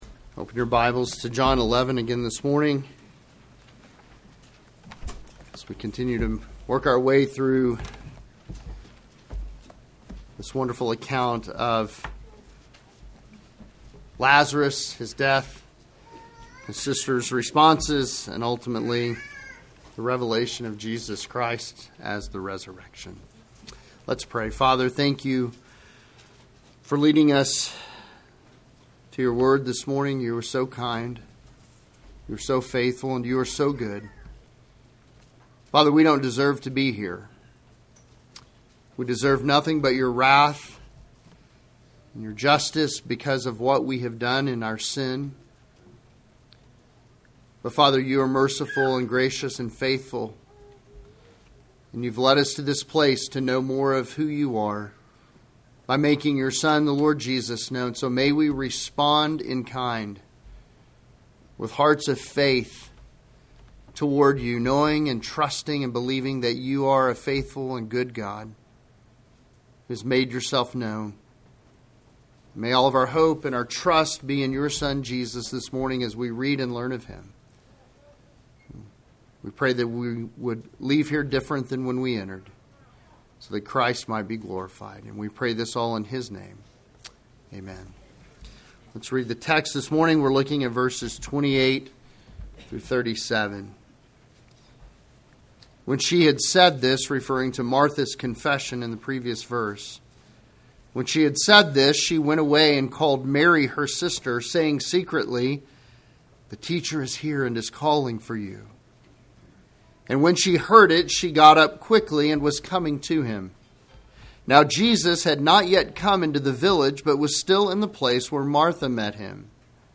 Sermons
sermon-february-2-2025.mp3